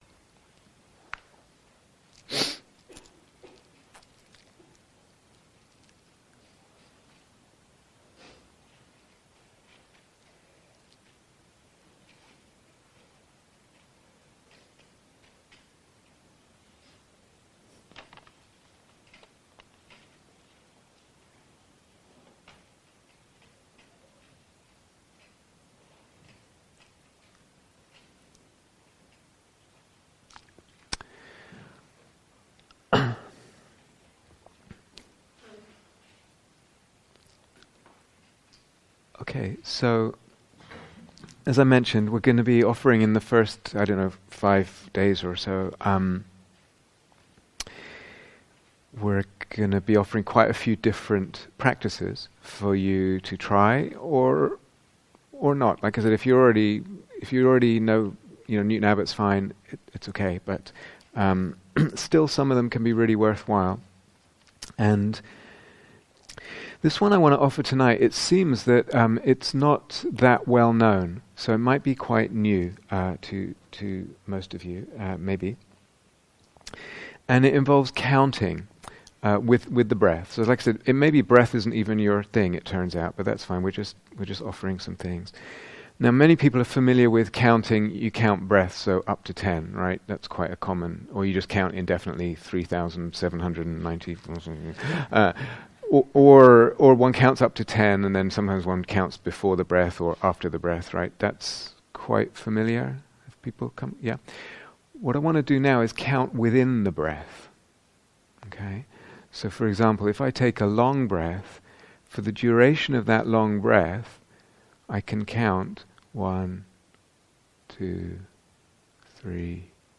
Counting Within the Breath (Guided Meditation)
Counting Within the Breath (Guided Meditation) Download 0:00:00 48:15 Date 17th December 2019 Retreat/Series Practising the Jhānas Transcription Okay.